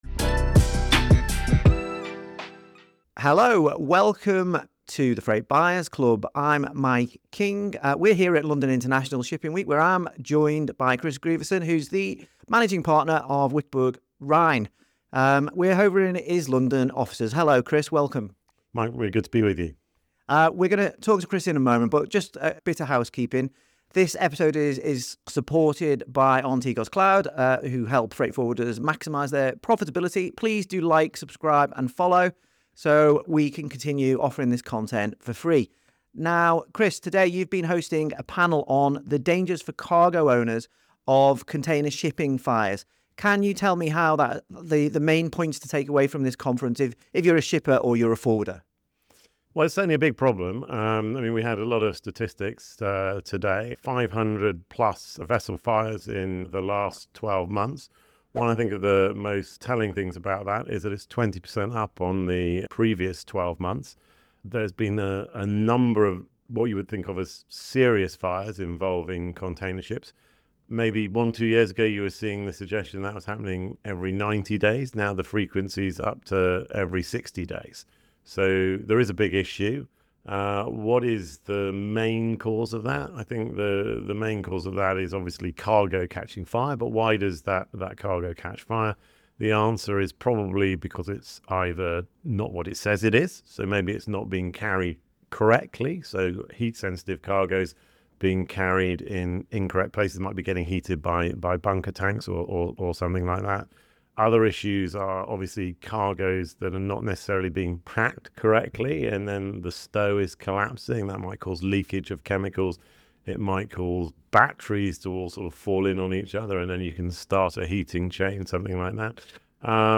recorded at London International Shipping Week